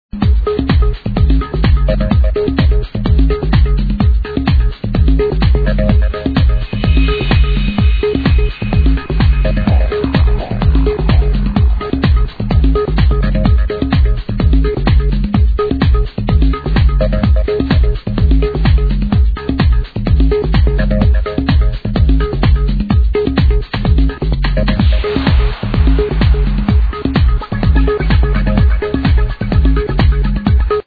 Groovy House track